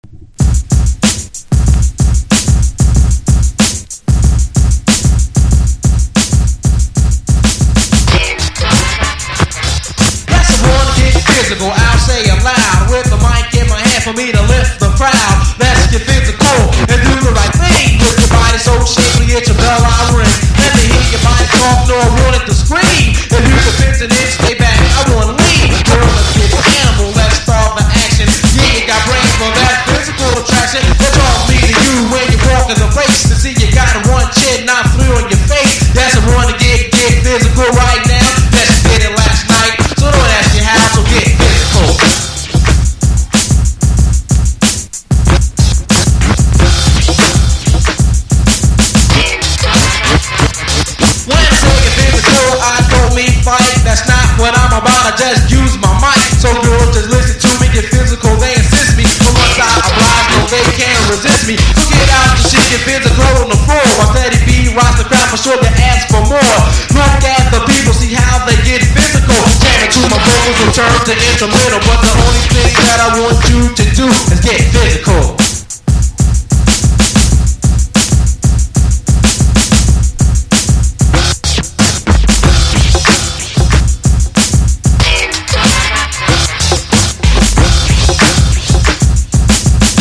フィルターのかかったラップと金属的なドラムは今では絶対に作られていないサウンド！